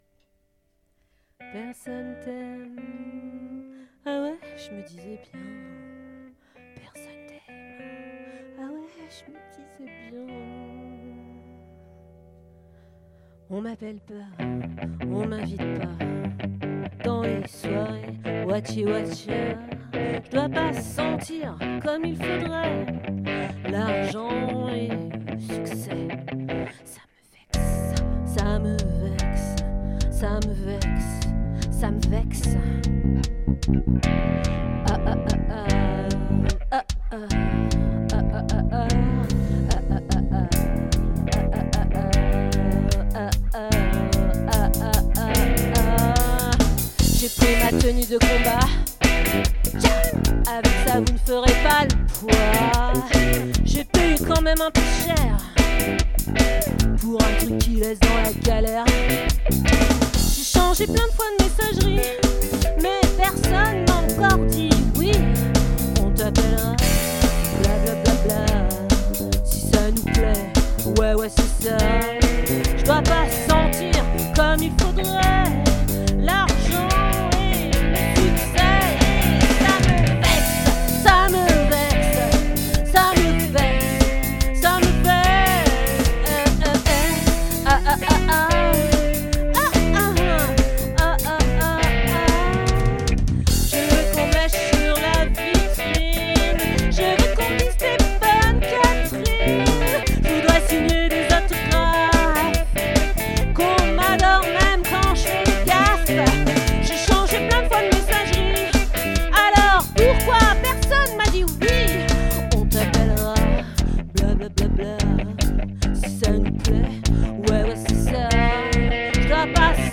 🏠 Accueil Repetitions Records_2022_03_16